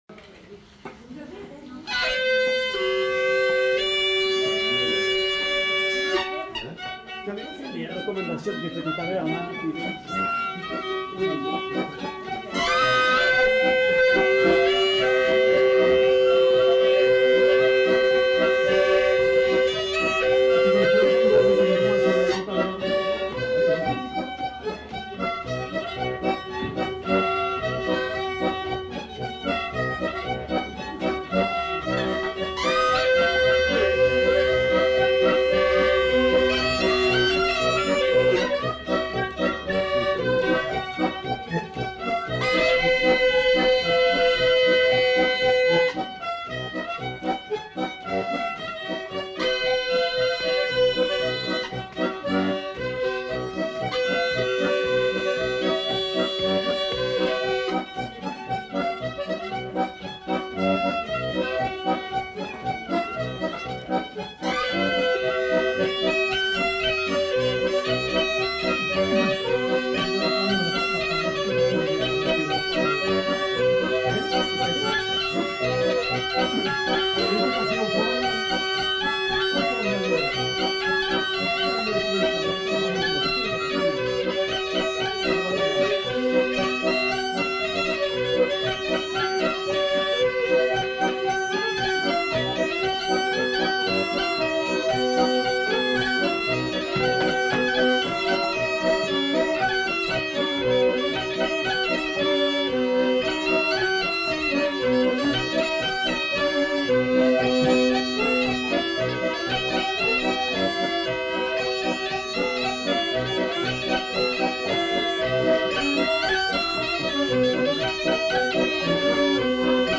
Rondeau
Aire culturelle : Agenais
Lieu : Foulayronnes
Genre : morceau instrumental
Instrument de musique : accordéon diatonique ; boha ; violon
Danse : rondeau
Notes consultables : Les enquêteurs apprennent le morceau en même temps.